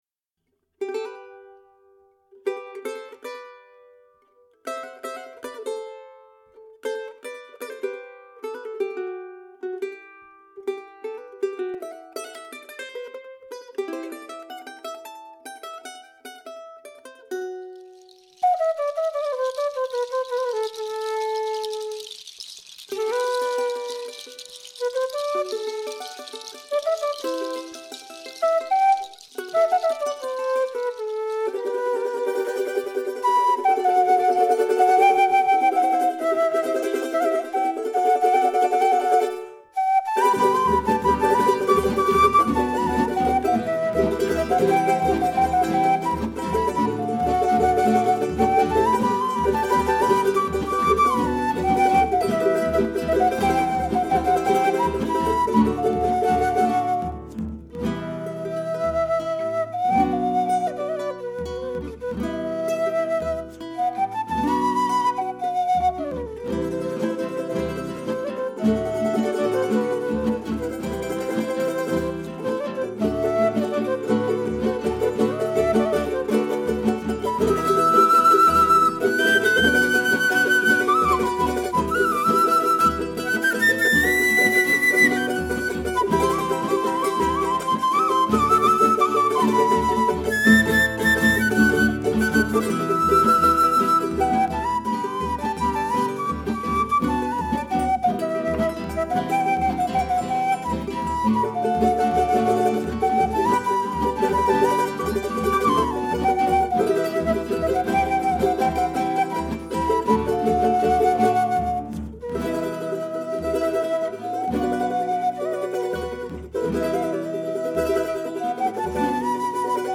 別々に録音した楽器やパソコンで作ったパーカッションをミックスして完成します。
スタジオは鍼灸院の待合室です。
フォルクローレの定番です。